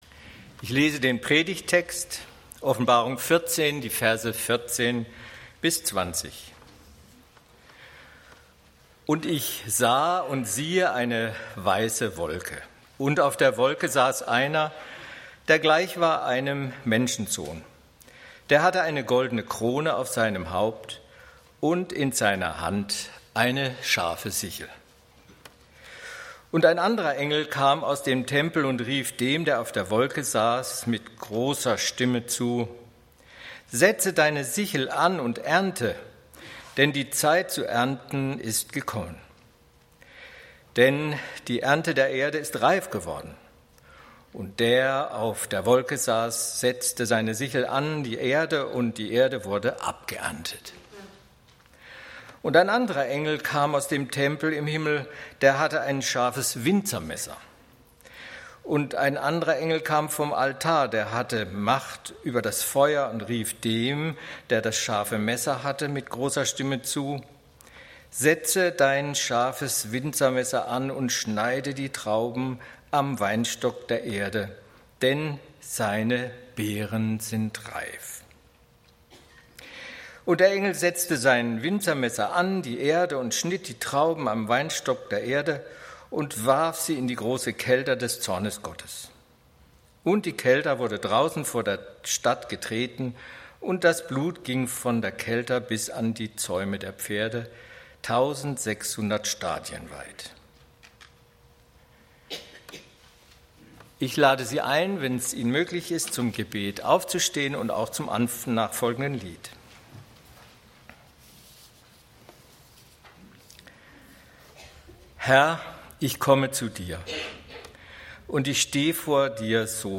Die Zeit der Ernte ist da (Offb. 14, 14-20) - Gottesdienst